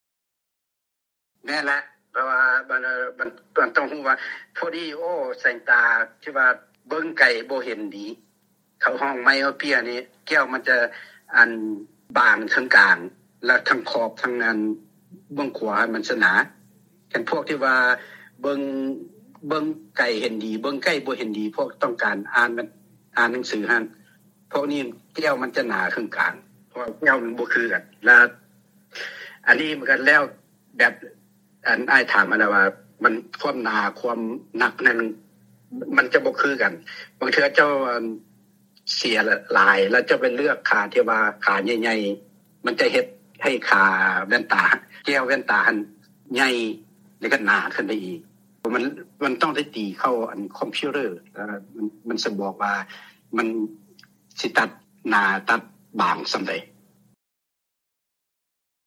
ວີໂອເອລາວ ສຳພາດ